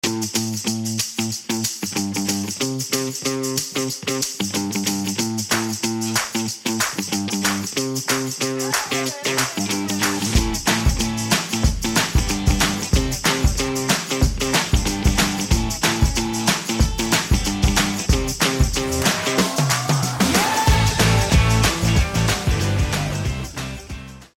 Female robot walking down the sound effects free download
Female robot walking down the street